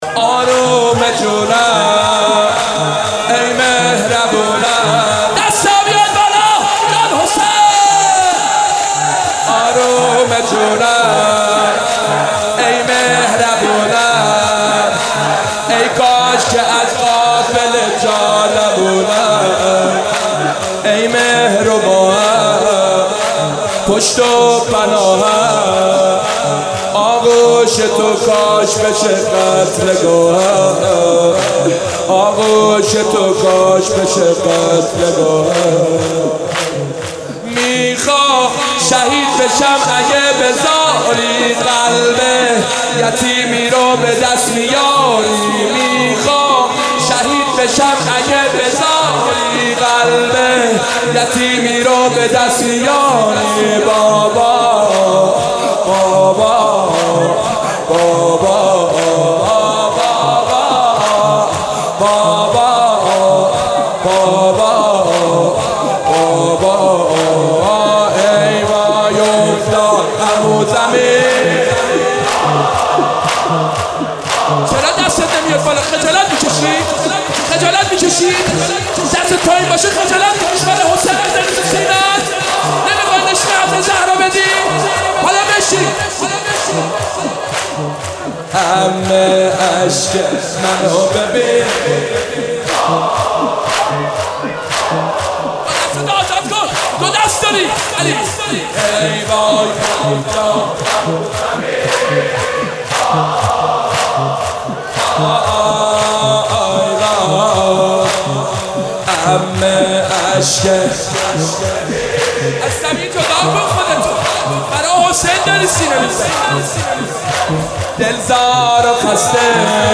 دانلود مداحی
شب 5 محرم 95